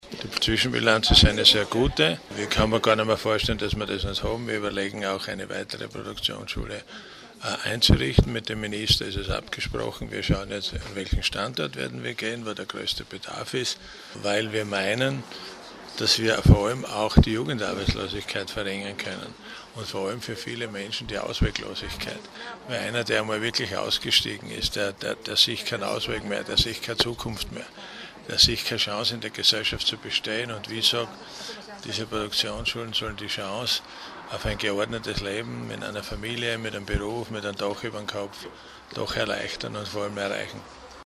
Zwei Jahre steirische Produktionsschulen - O-Töne
Soziallandesrat Siegfried Schrittwieser: